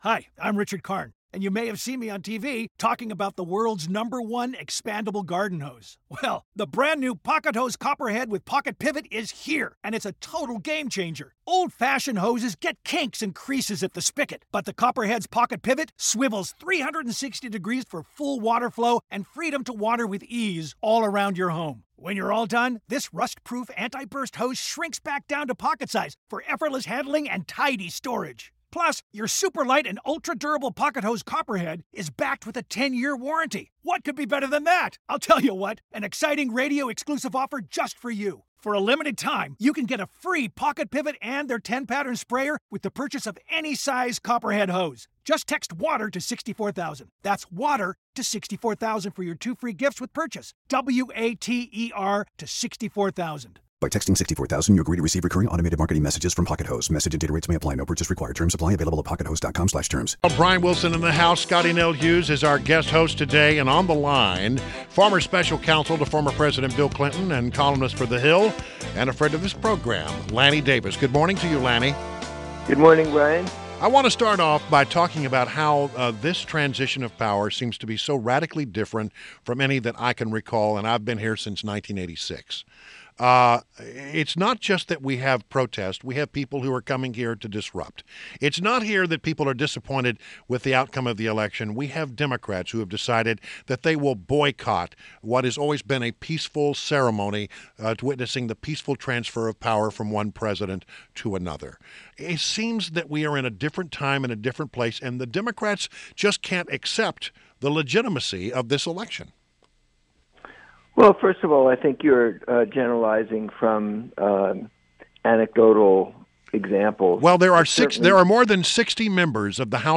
INTERVIEW — LANNY DAVIS – former special counsel to former President Bill Clinton and columnist for The Hill